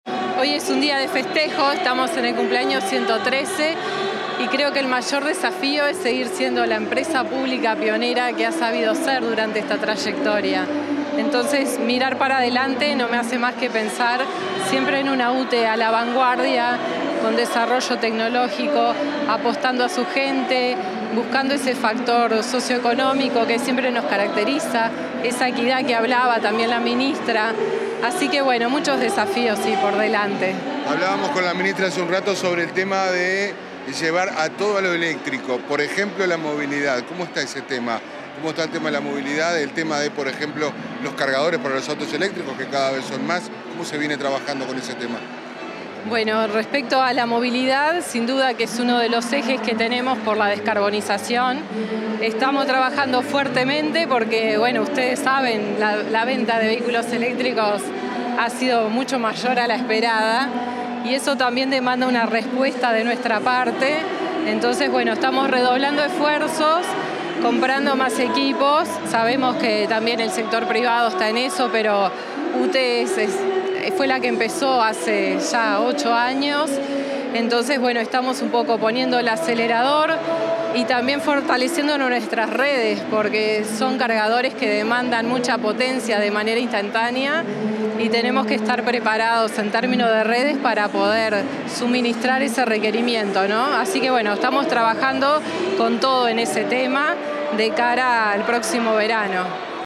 La presidenta de UTE, Andrea Cabrera, dialogó con la prensa tras participar en la celebración del 113 aniversario de UTE.